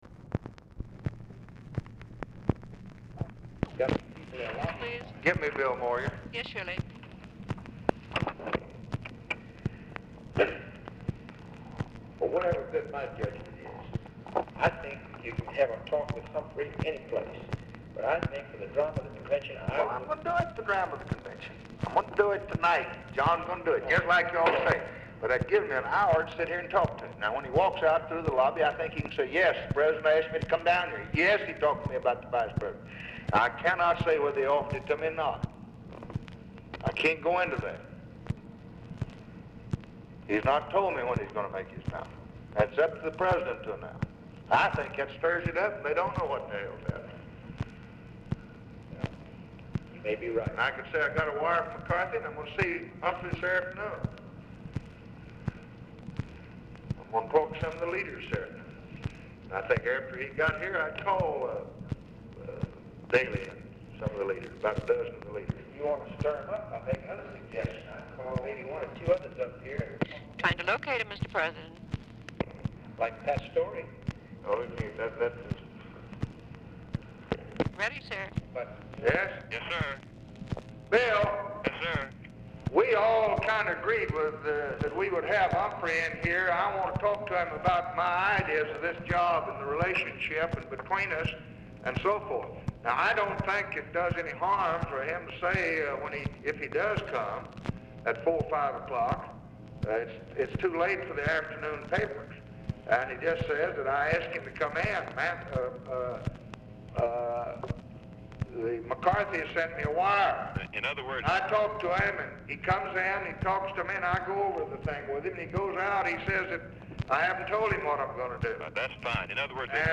Telephone conversation # 5221, sound recording, LBJ and BILL MOYERS, 8/26/1964, 12:50PM
OFFICE CONVERSATION PRECEDES CALL; LBJ ON SPEAKERPHONE, MEETING WITH JACK VALENTI AT TIME OF CALL
Format Dictation belt